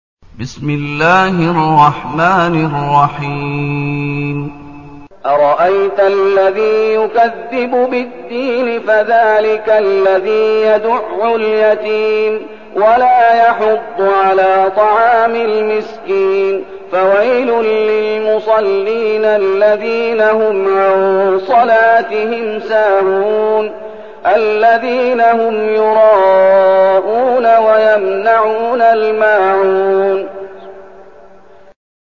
المكان: المسجد النبوي الشيخ: فضيلة الشيخ محمد أيوب فضيلة الشيخ محمد أيوب الماعون